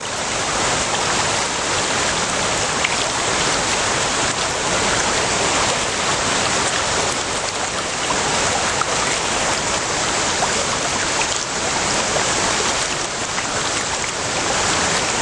描述：2010年7月4日使用Zoom H2进行立体声现场录音
Tag: 小溪 现场录音 汩汩 溪流